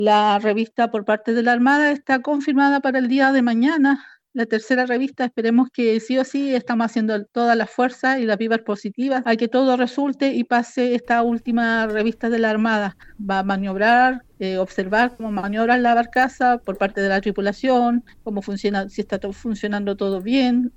En conversación con Radio Bío Bío